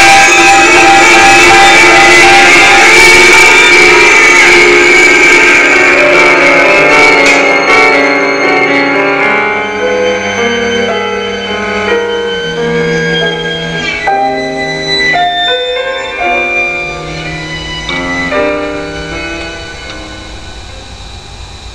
***This summer at Walden i composed a piece i liked a lot, Piano Trio No. 1. though the following sound bites are not the best quality, I still think you should try and listen to them.
HERE to hear a BIG moment in the piece, followed by some of its wind-down...
I am sorry for the quality, but hey, I'm not professional.